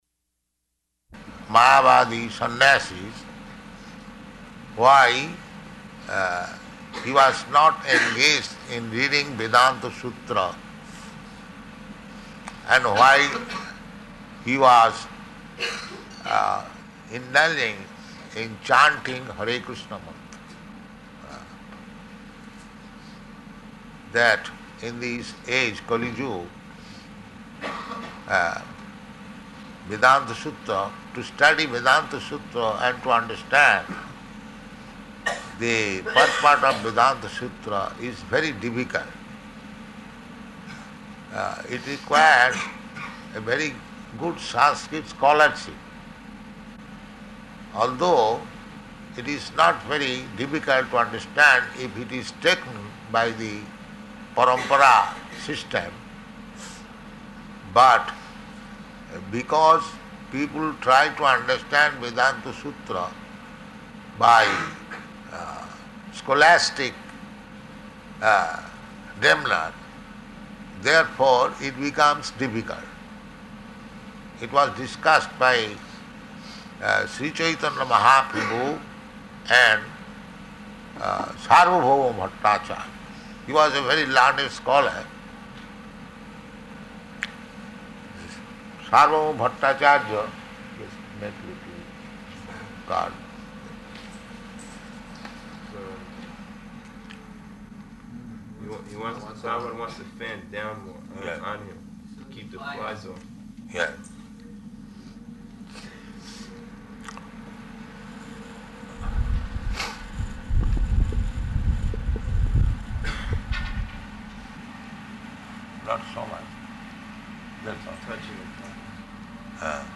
Location: Vṛndāvana
[fan noise resumes] Prabhupāda: Yes.